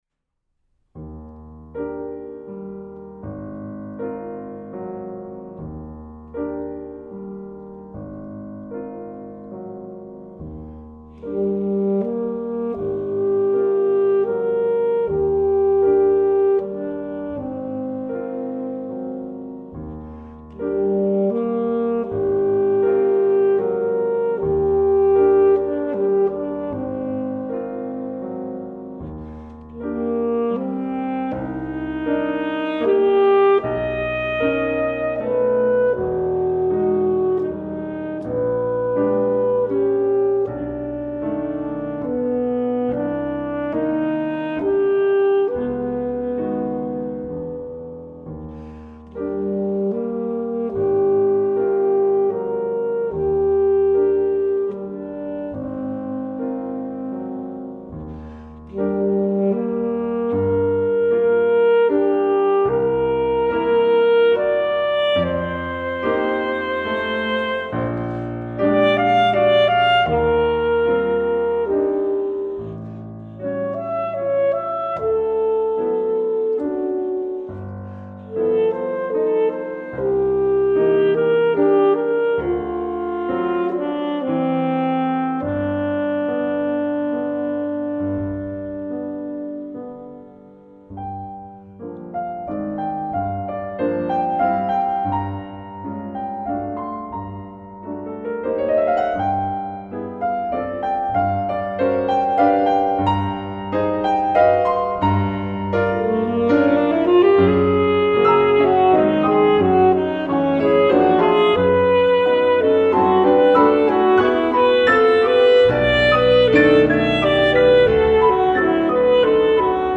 2. Slowly and gently